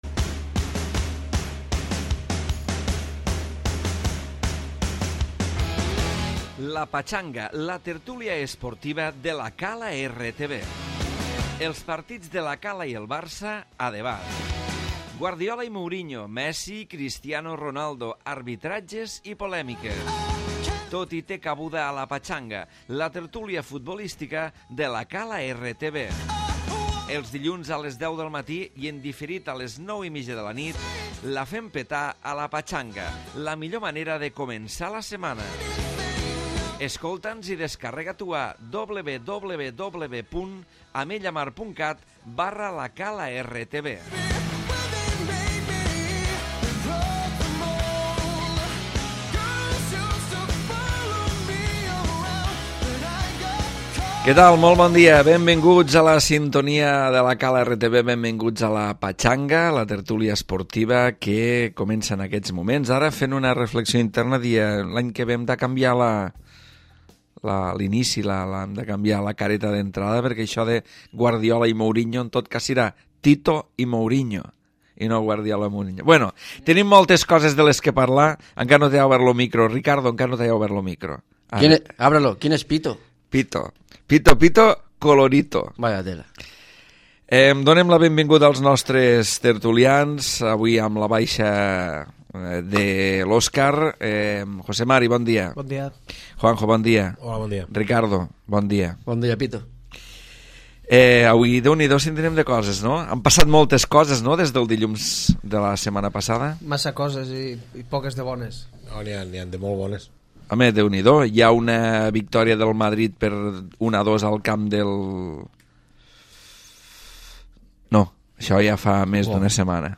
L'eliminació del Barça i el Madrid de la Champions, l'anunci de Guardiola de deixar el club i la possible lliga del Madrid acaparen l'atenció dels tertulians.